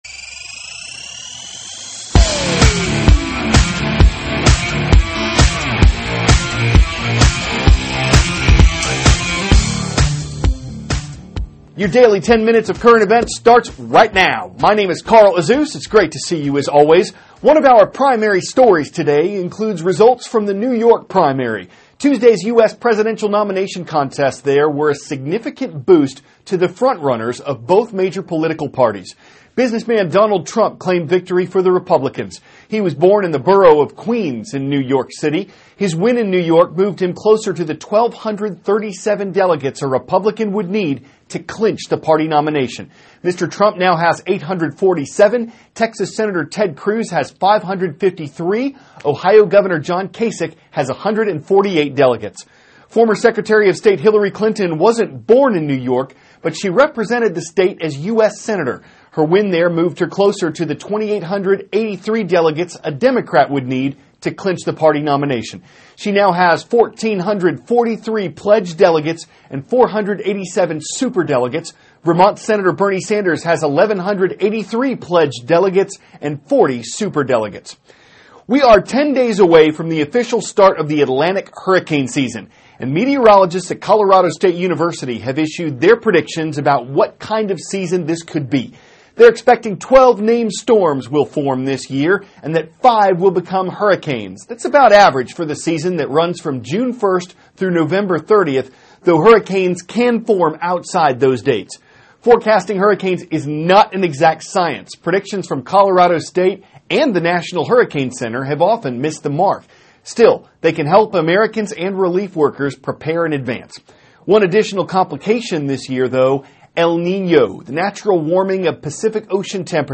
(CNN Student News) -- April 21, 2016 Trump & Clinton Win New York Primary; Predictions are Mae for the Atlantic Hurricane Season; Palmyra`s Ancient Triumphal Arch Resurrected in London`s Trafalgar Square. Aired 4-4:10a ET THIS IS A RUSH TRANSCRIPT.